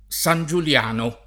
San JulL#no] top.